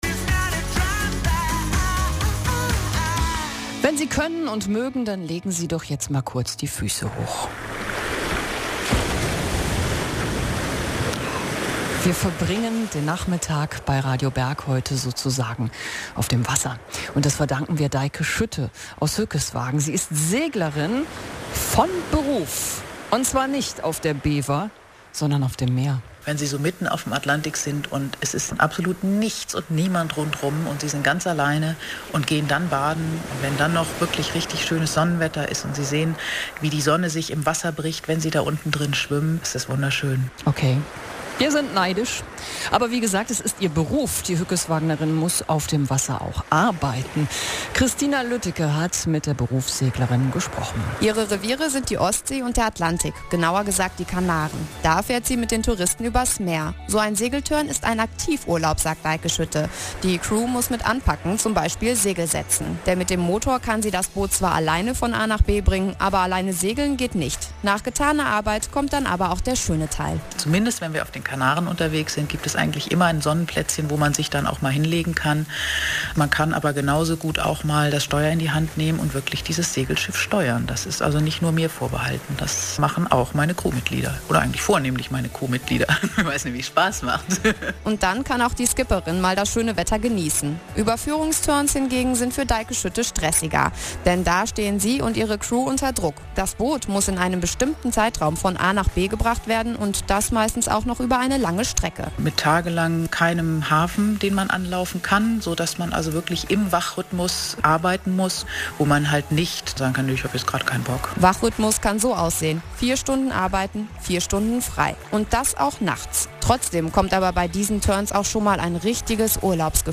Interview mit Teil 1 Radio Berg vom 27.